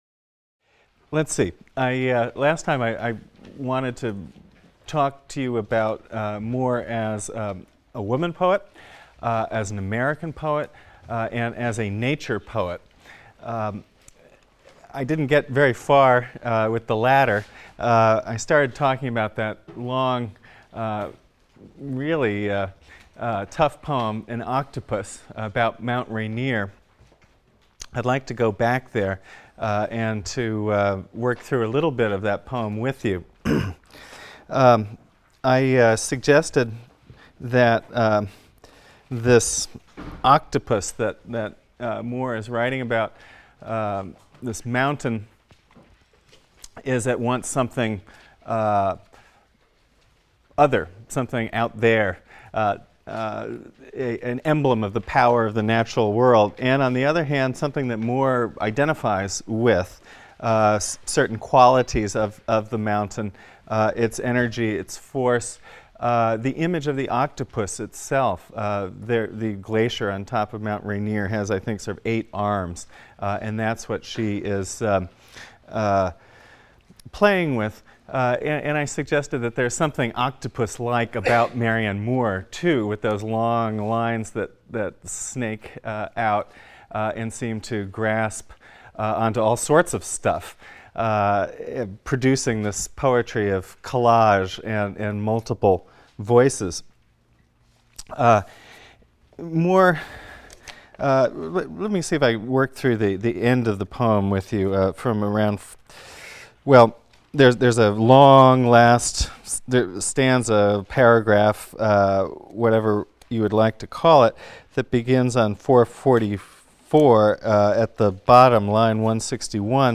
ENGL 310 - Lecture 18 - Marianne Moore (cont.) | Open Yale Courses